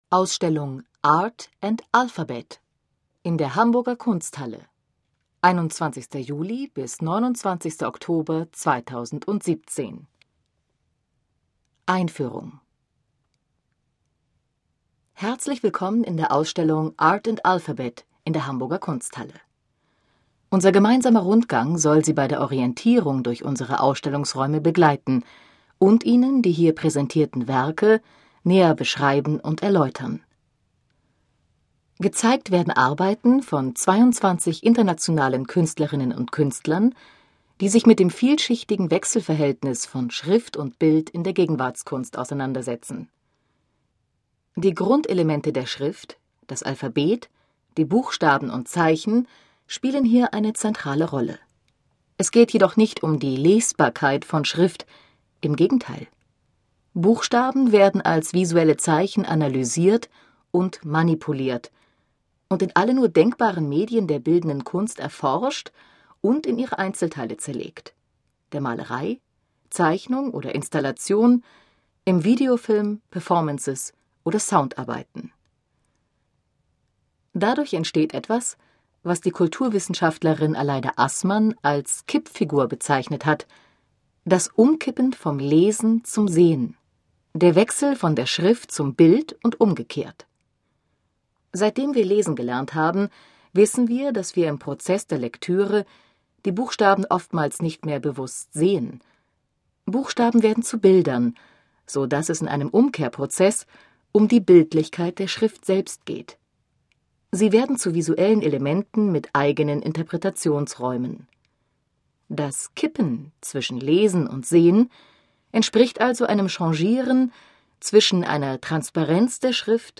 Bildbeschreibungen aller Kunstwerke